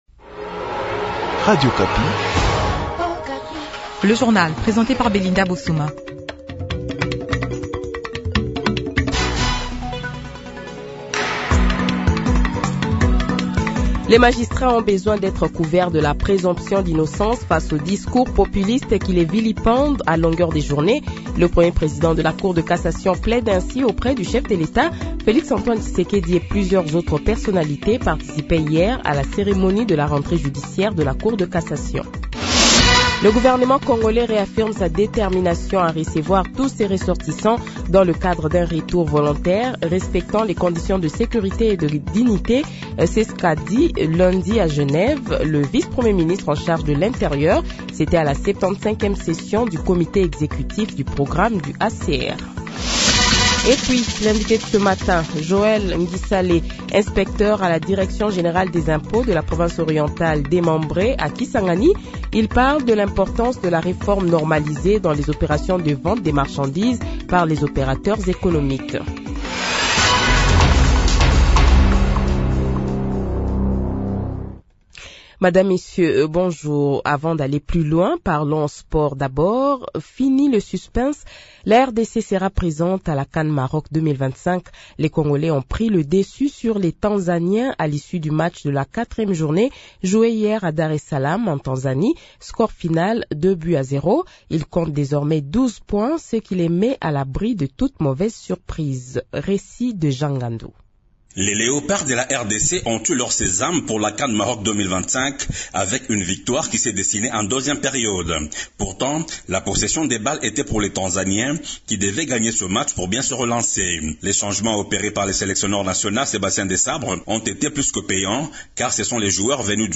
Le Journal de 7h, 16 Octobre 2024 :